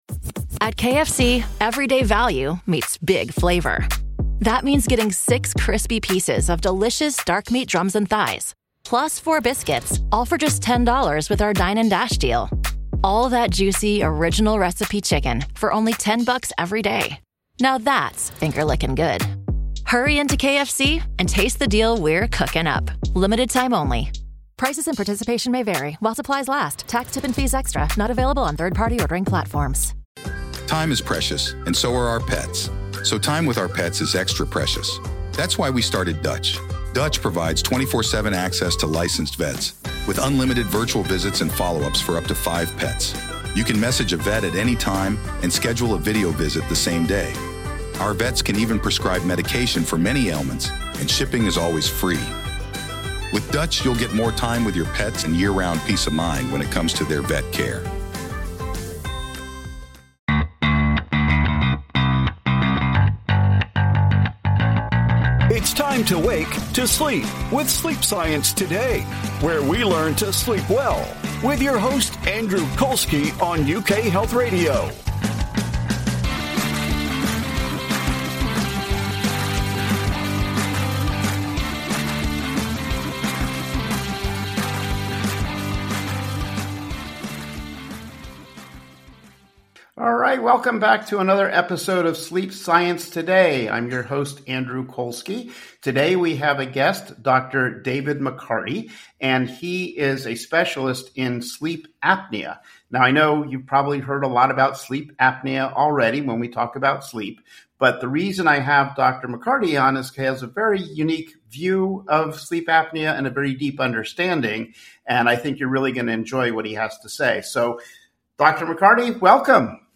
Doctors have learned that restful sleep is critical for your physical and mental health. You will hear from renowned sleep experts as they share the latest information about how to sleep better with science.